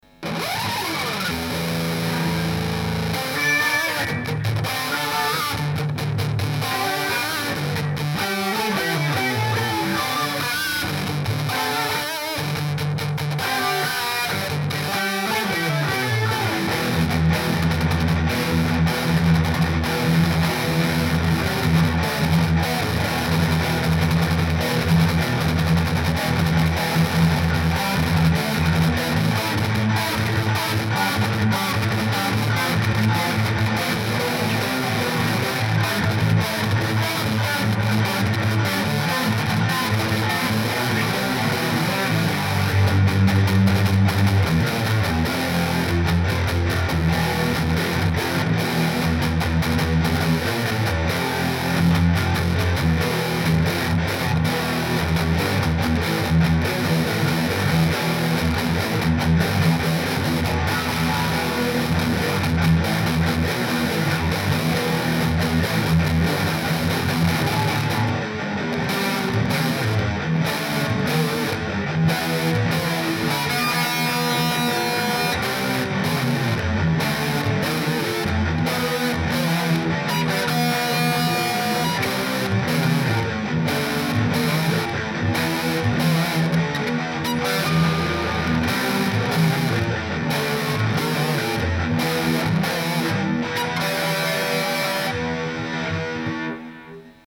Chcesz posłuchać jak gram hard core kliknij